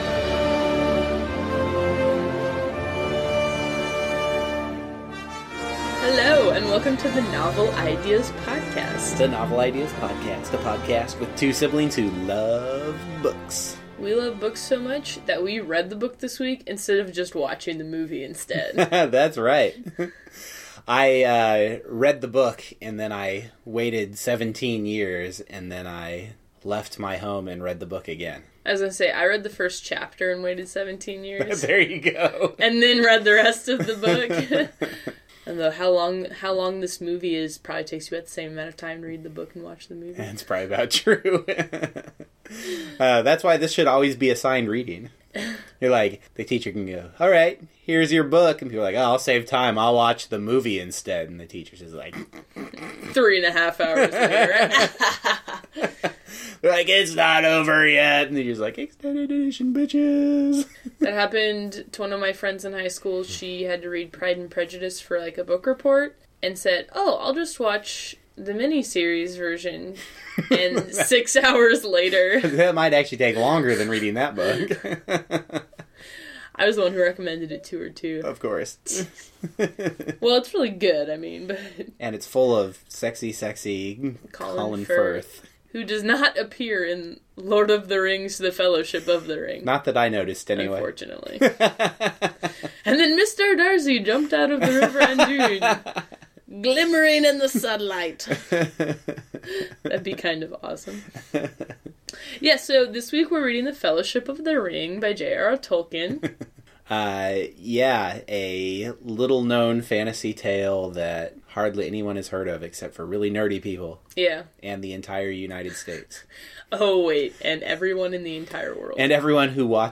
I also attempted a tweak with my audio processing, so if it sounds weird on your listening device of preference, please leave a comment.